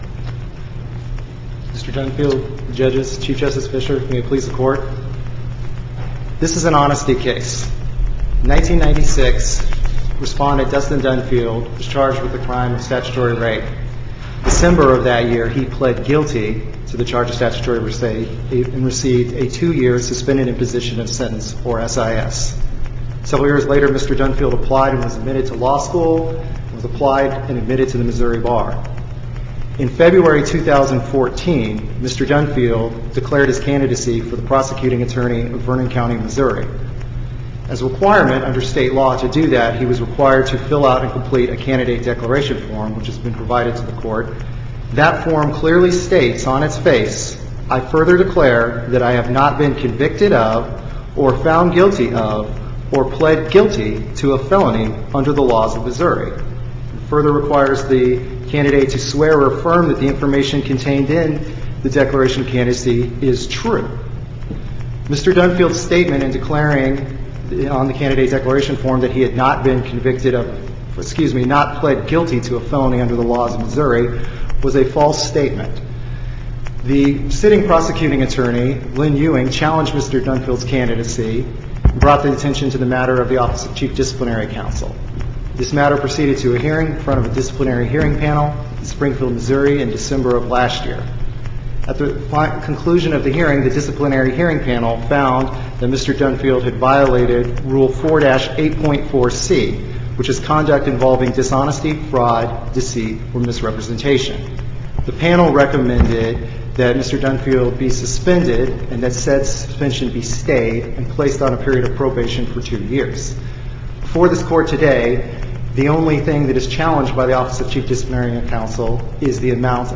MP3 audio file of arguments in SC96103